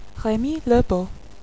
Pronounciation)